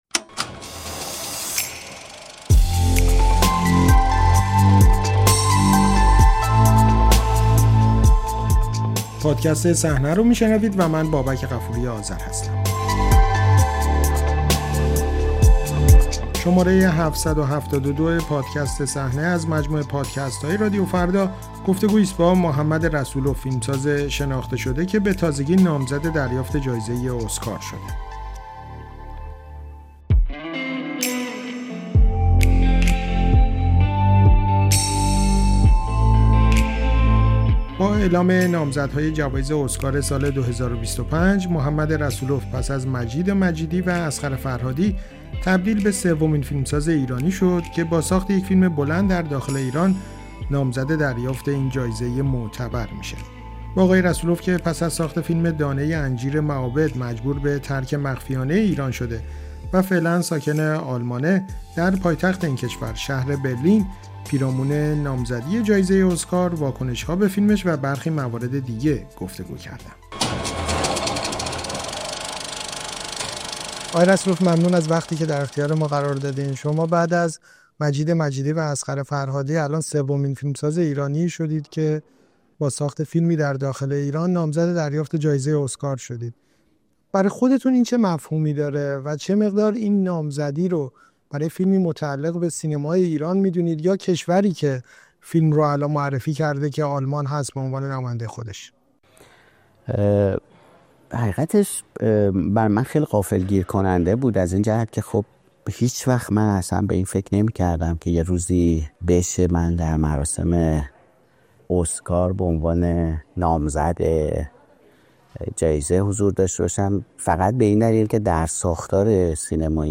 گفت‌وگو با محمد رسول‌اف پس از نامزدی در جوایز اسکار